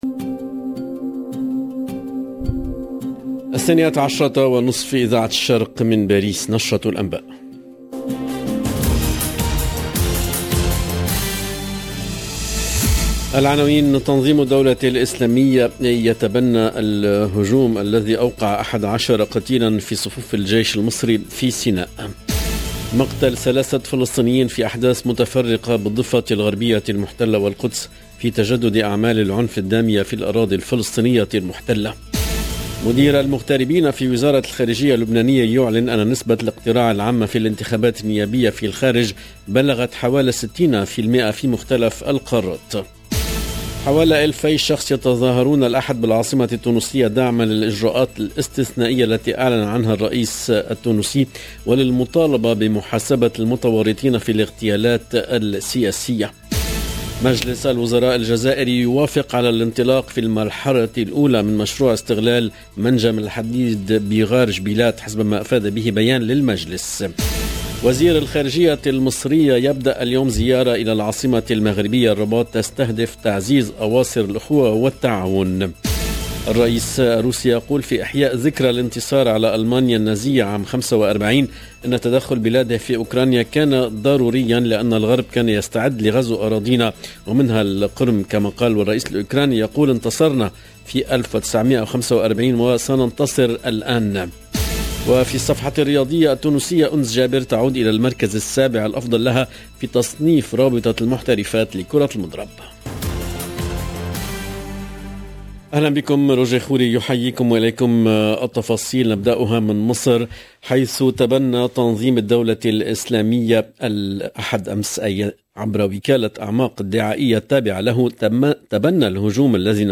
LE JOURNAL EN LANGUE ARABE DE MIDI 30 DU 9/05/22